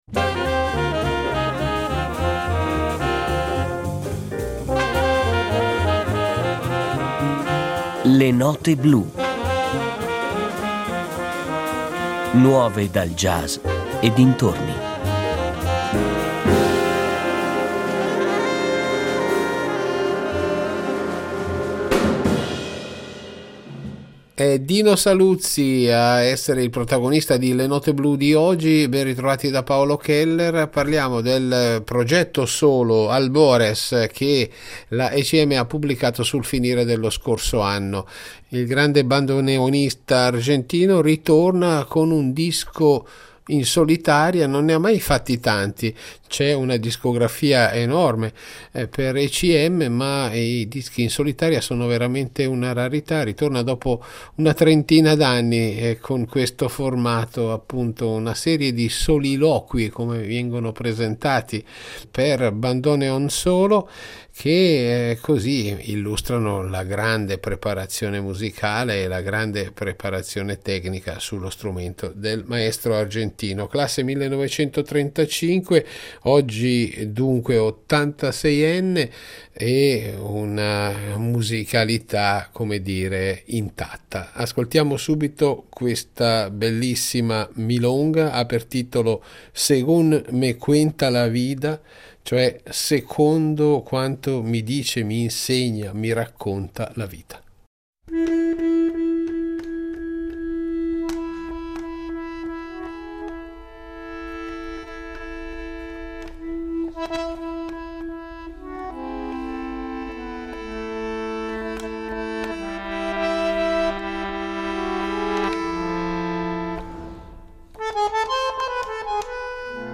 bandoneon
È una raccolta di soliloqui, molto intima